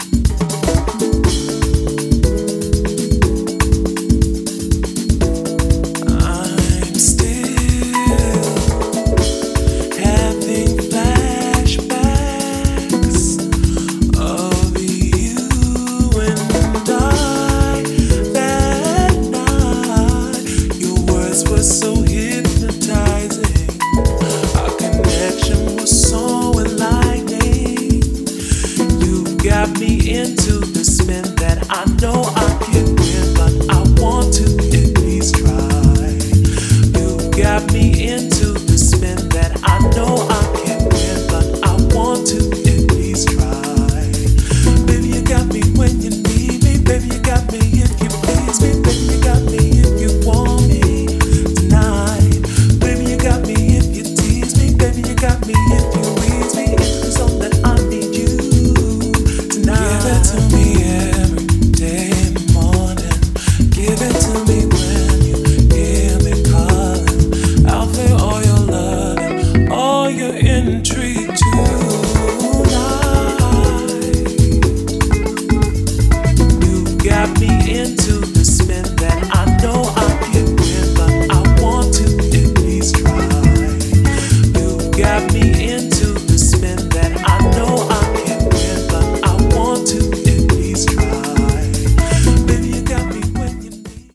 deep house, soul, and jazz-funk